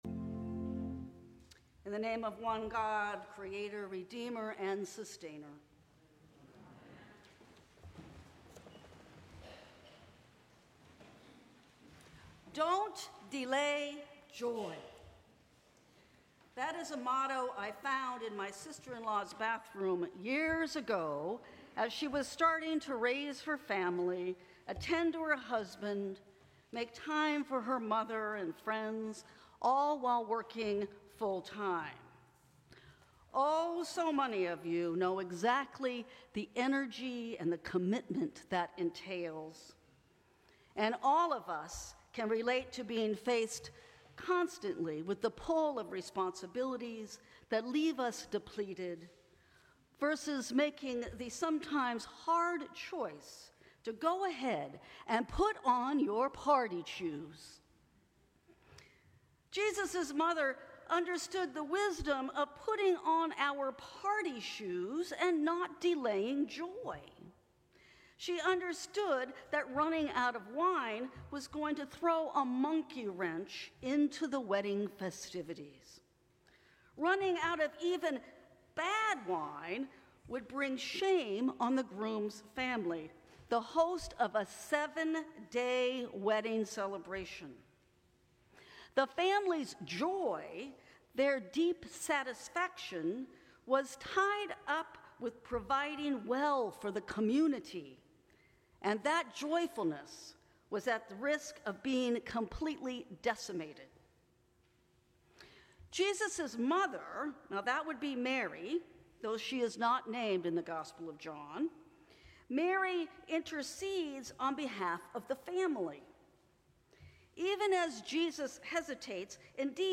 Sermons from St. Cross Episcopal Church Second Sunday after the Epiphany Jan 22 2025 | 00:10:02 1x 00:00 / 00:10:02 Subscribe Share Apple Podcasts Spotify Overcast RSS Feed Share Link Embed